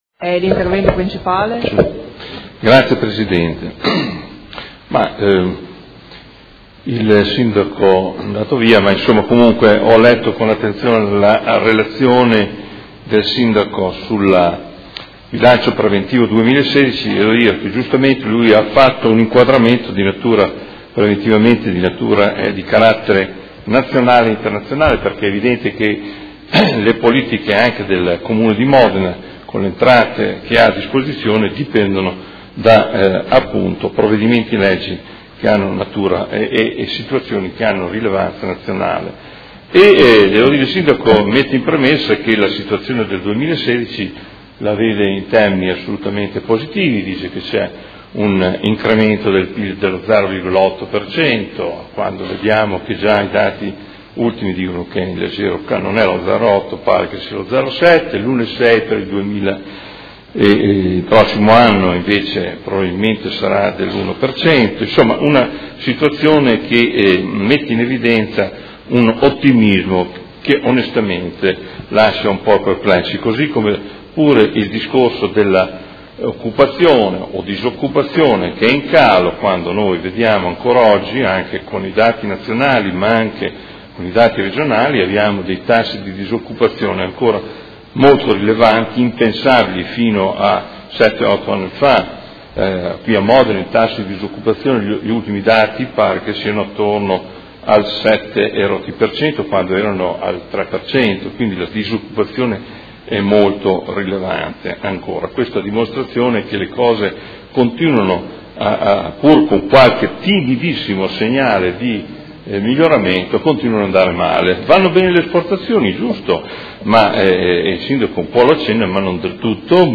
Seduta del 25 febbraio. Approvazione Bilancio: Dibattito